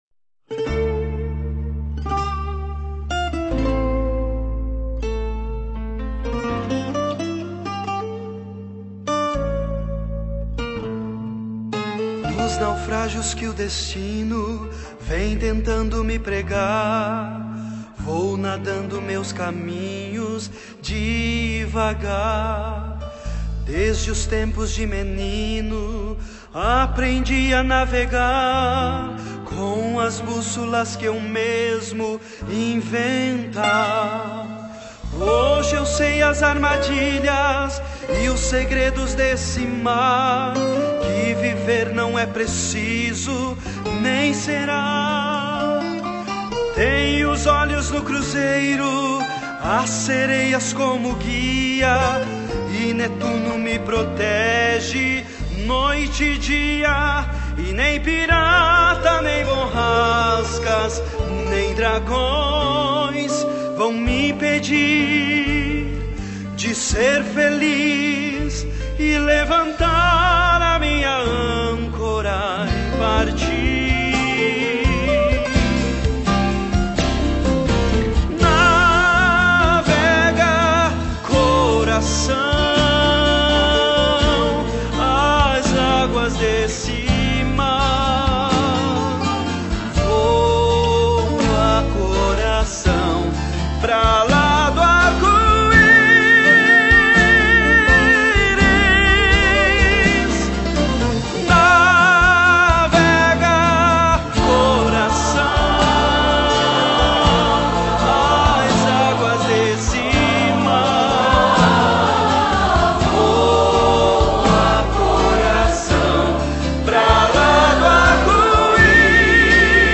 Pianistas